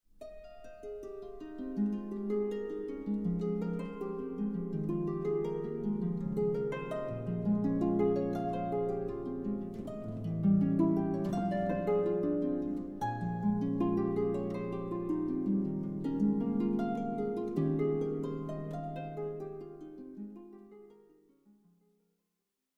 A beautiful cd with 77 minutes harp solo music.